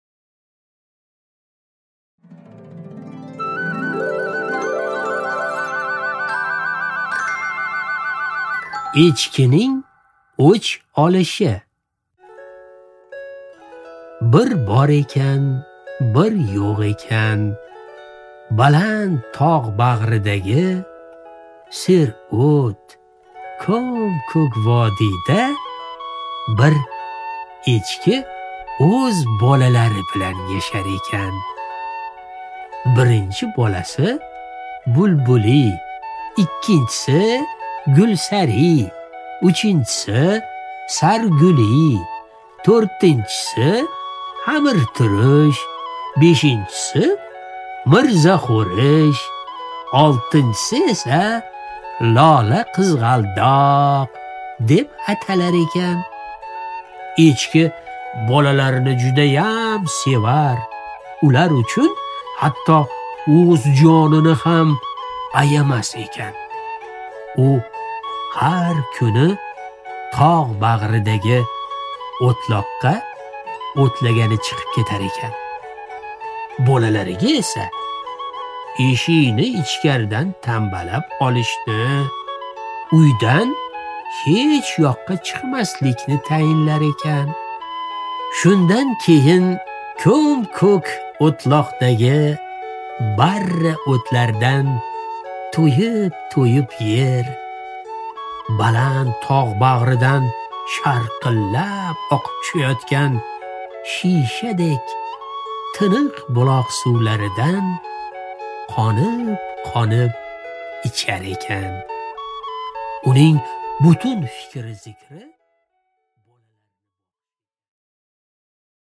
Аудиокнига Echkining o’ch olishi